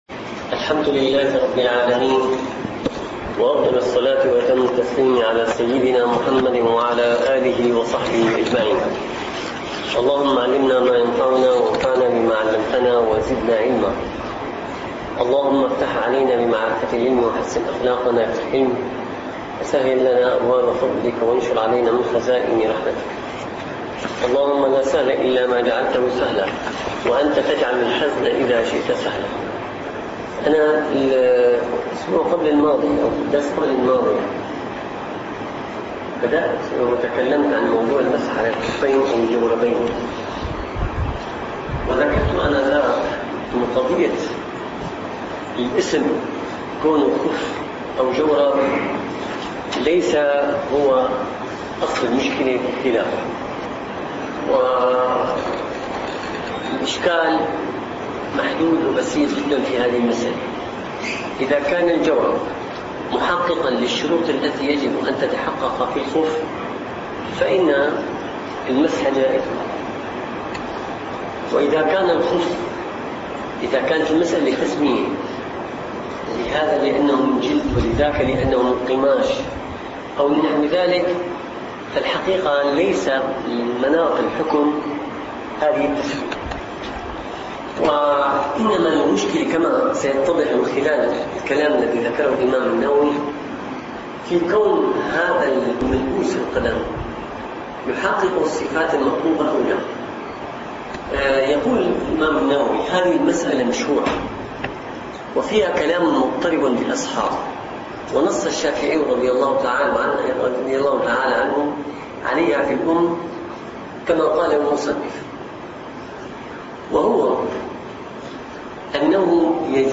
- الدروس العلمية - الفقه الشافعي - المنهاج القويم شرح المقدمة الحضرمية - تكملة المسح على الخفين+ نواقض الوضوء+ فصل فيما يحرم بالحدث والمراد به الأصغر عند الإطلاق.